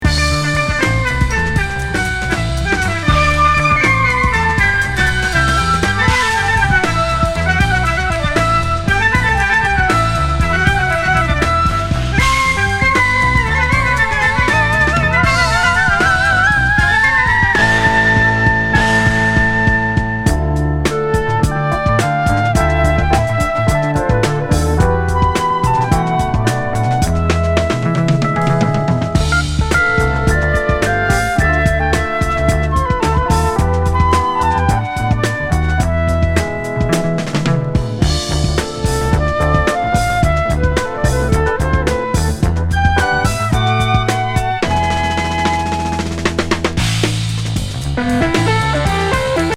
スイス出身ジャズ・ピアニスト～コンポーザー。